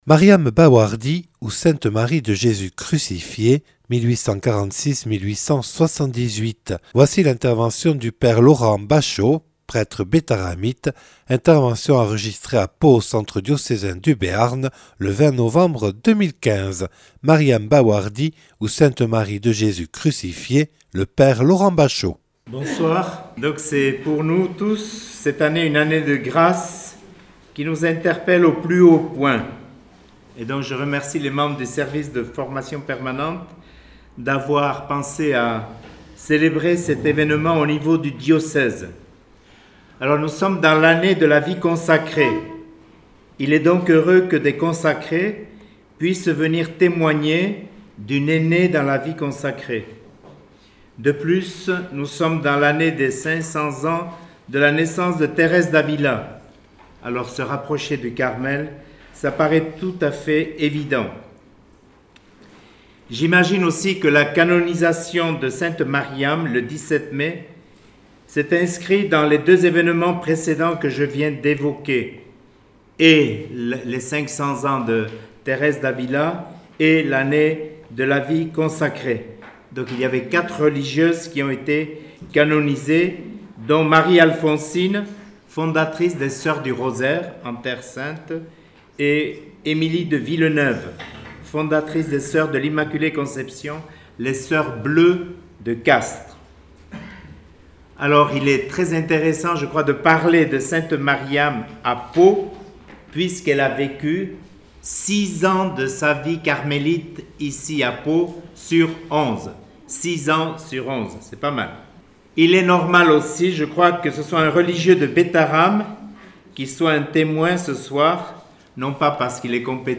Enregistrée le 20/11/2015 au Centre diocésain du Béarn à Pau.